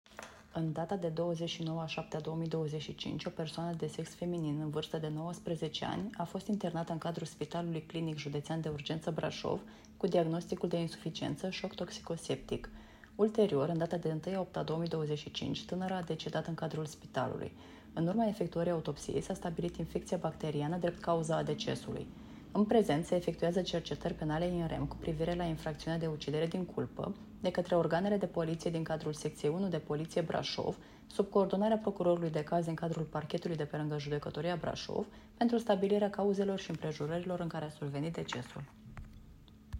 Cauza decesului a fost stabilită în urma autopsiei, spune procurorul de caz, prim procuror la Parchetul de pe lângă Judecătoria Brașov, Anca Loredana Gheorghiu: